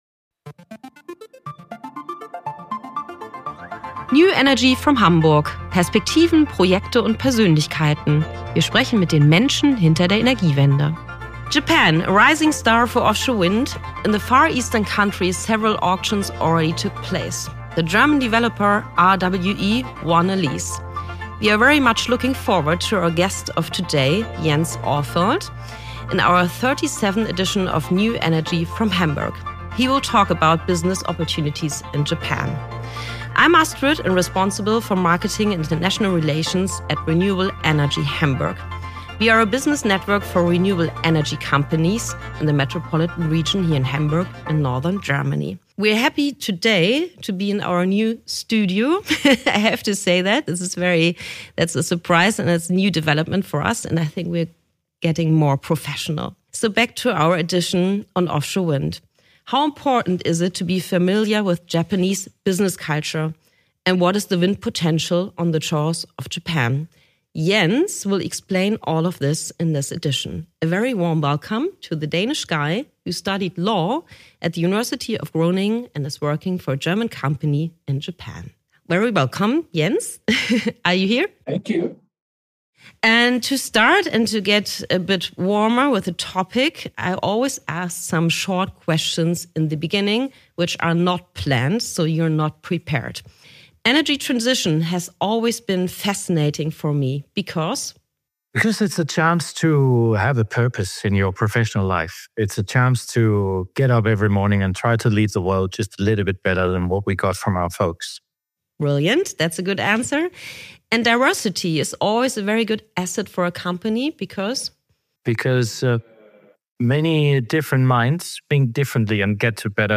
Wie Japan den Offshore-Wind-Markt für Entwickler öffnet - Interview